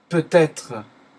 Ääntäminen
IPA : /ˈmaɪt/